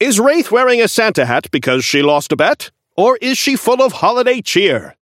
Wraith voice line - Is Wraith wearing a Santa hat because she lost a bet? Or is she full of holiday cheer?
Newscaster_seasonal_wraith_unlock_01.mp3